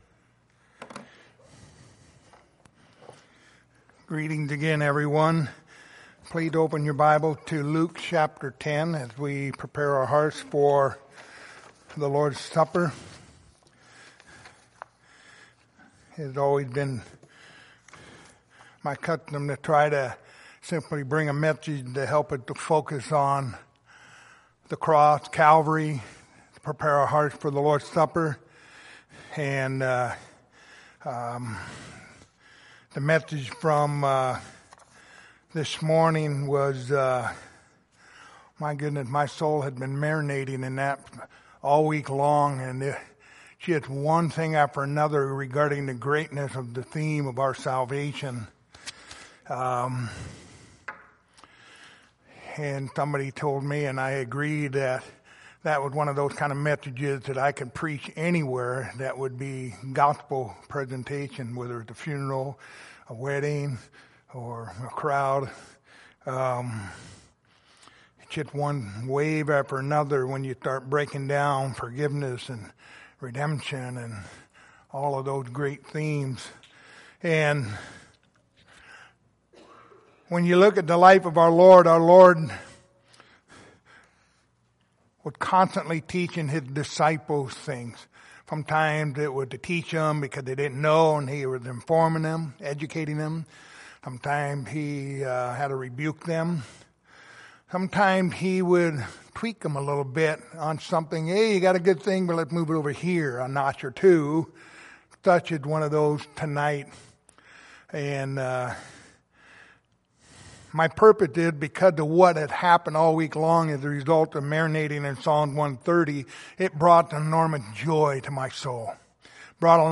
Lord's Supper Passage: Luke 10:1-3, Luke 10:16-20 Service Type: Lord's Supper